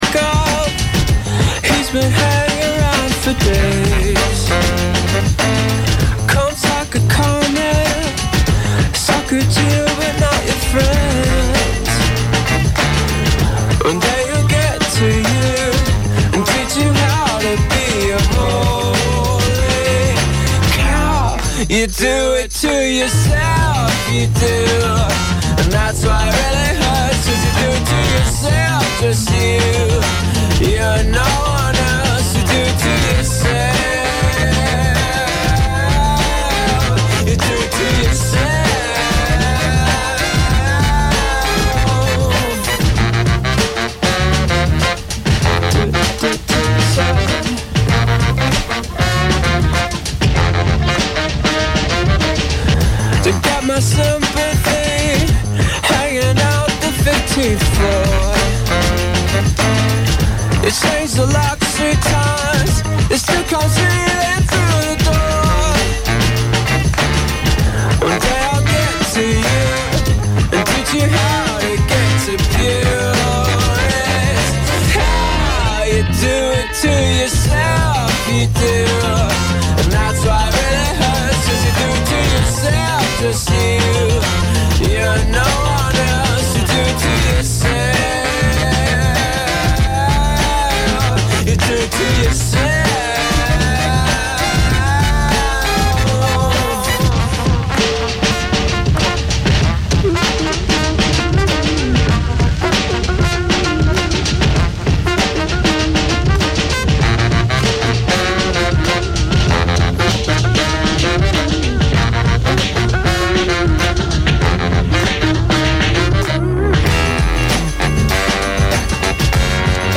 Scopri le gemme rare degli anni '60 e '70: psichedelia, freakbeat, punk e folk rock.